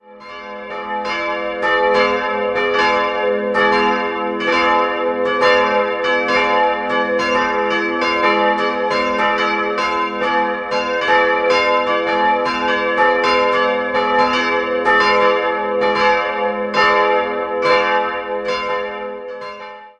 In der Barockzeit erhielt er sein heutiges Aussehen und das Langhaus entstand neu. 3-stimmiges TeDeum-Geläute: a'-c''-d'' Die kleine Glocke wurde 1716 von Johann Balthasar Heroldt in Nürnberg, die beiden anderen 1951 von Karl Czudnochowsky in Erding gegossen.